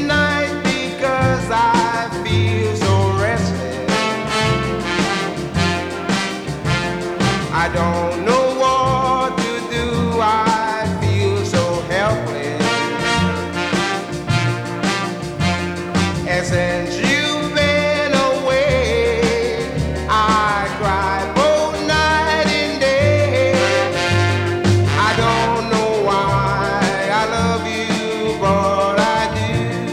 • Rock & Roll